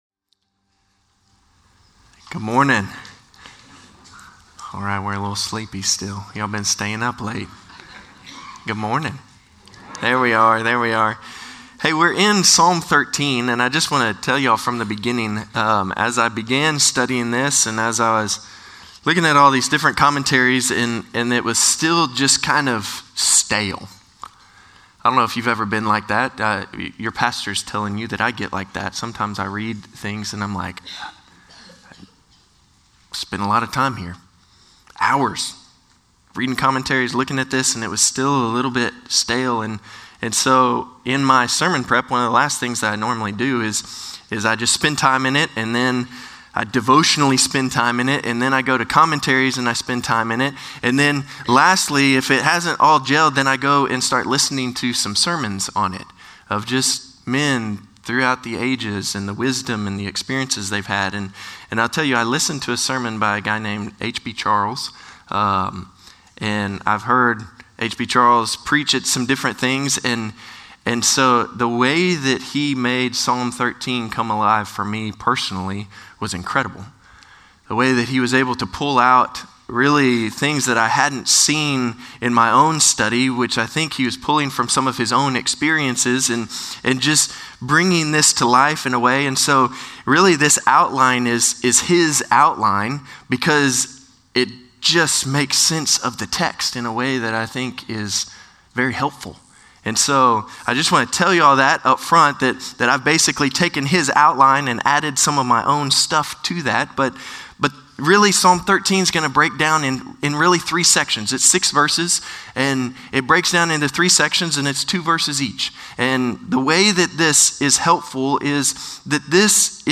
Norris Ferry Sermons Dec. 29, 2024 -- The Book of Psalms -- Psalm 13 Dec 29 2024 | 00:34:18 1x 00:00 / 00:34:18 Subscribe Share Spotify RSS Feed Share Link Embed